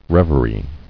[rev·er·ie]